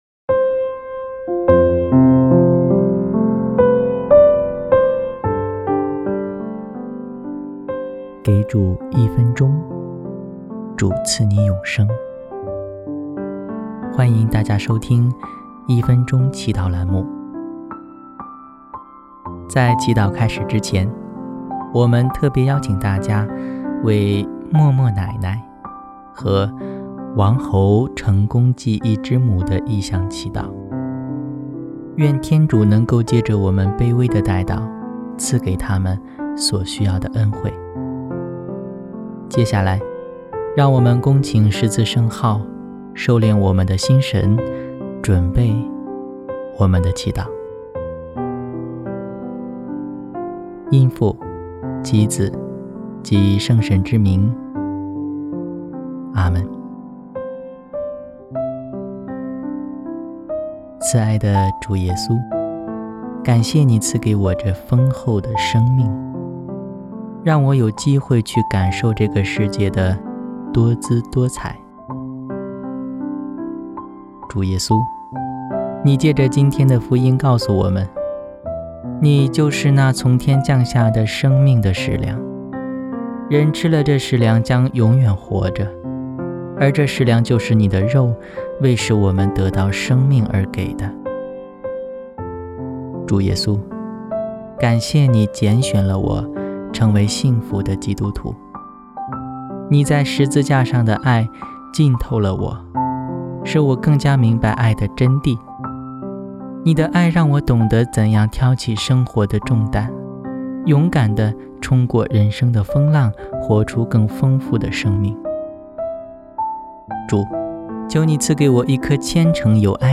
【一分钟祈祷】|主，求祢赐给我一颗虔诚有爱的心（8月8日）